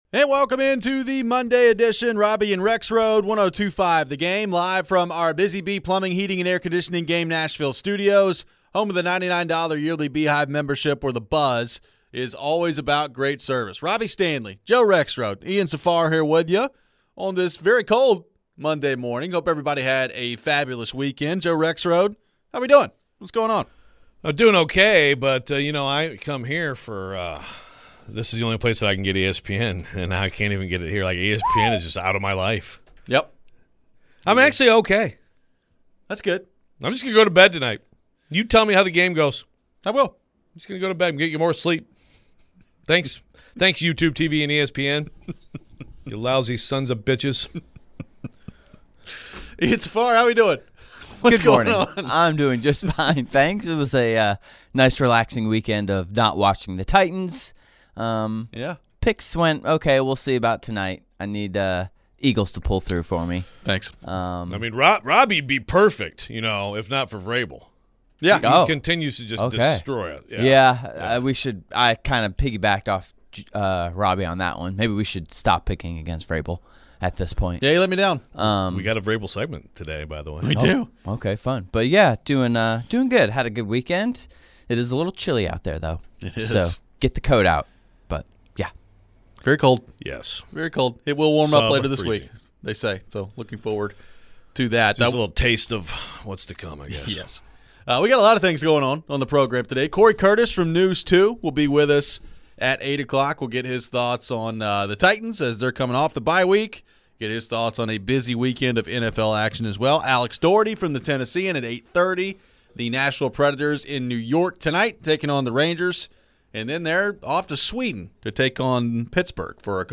We react to the weekend of action and take your phones. Vanderbilt got the OT win over Auburn to keep its playoff hopes alive.